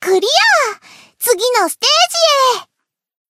贡献 ） 分类:蔚蓝档案语音 协议:Copyright 您不可以覆盖此文件。
BA_V_Momoi_Battle_Victory_1.ogg